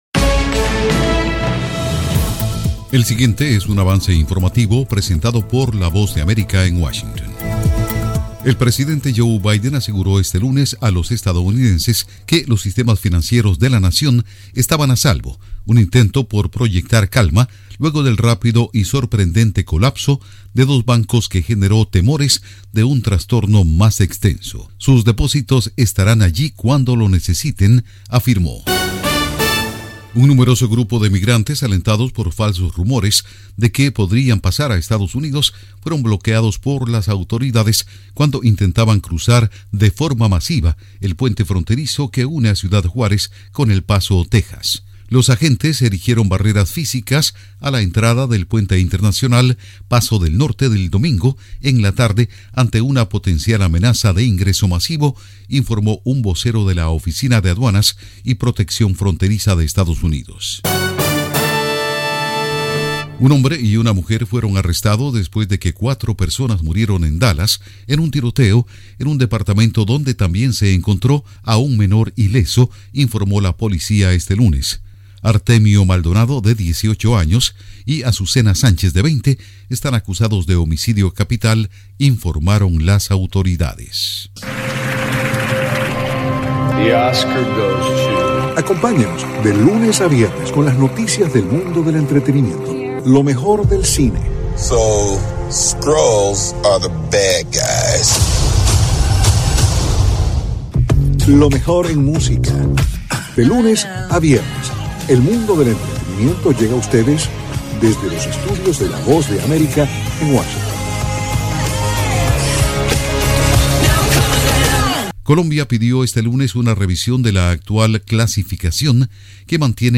El siguiente es un avance informativo presentado por la Voz de América en Washington